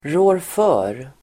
Ladda ner uttalet
Uttal: [rå:rf'ö:r]